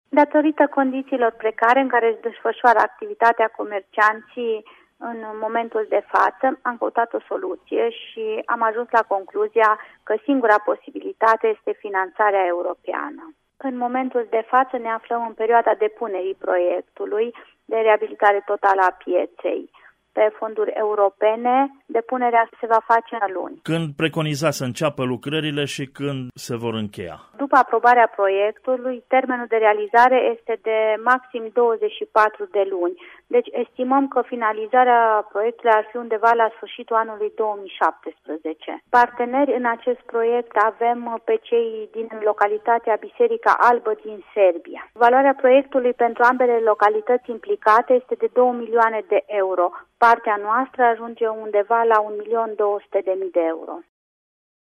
Ascultaţi declaraţia viceprimarului Lorena Ion:
Viceprimar-Lorena-Ion.mp3